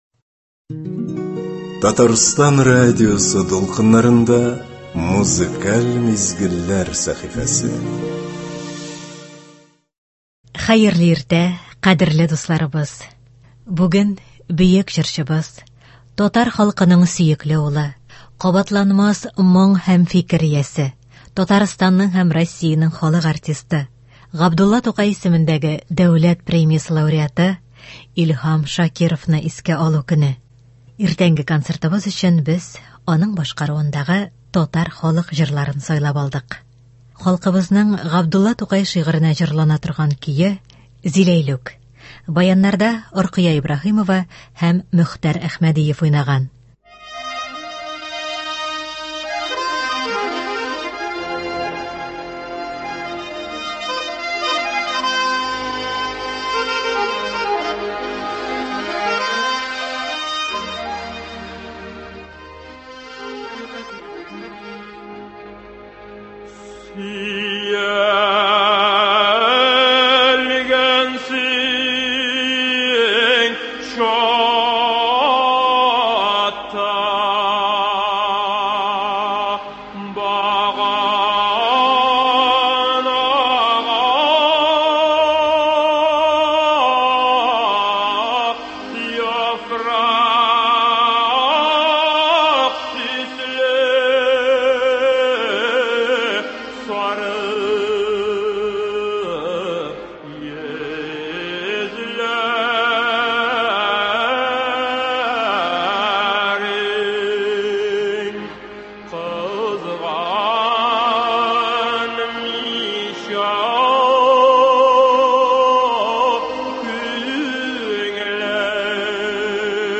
Иртәнге концертыбыз өчен без аның башкаруындагы татар халык җырларын сайлап алдык.